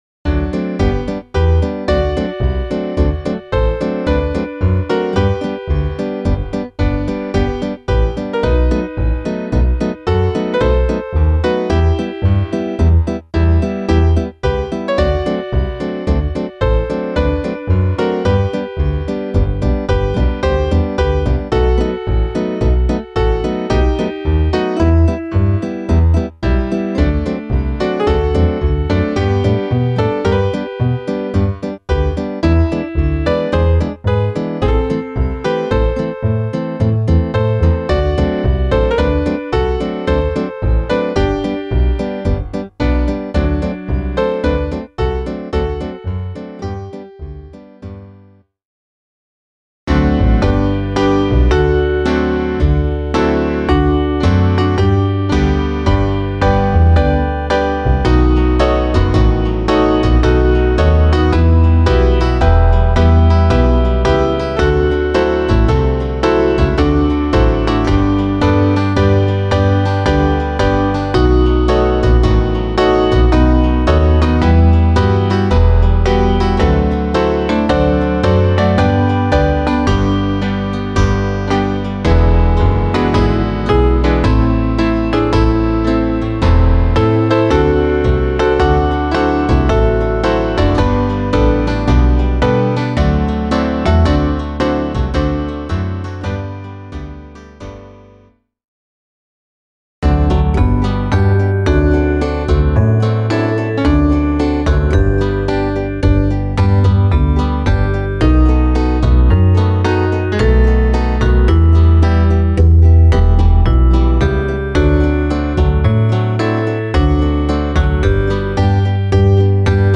Variationen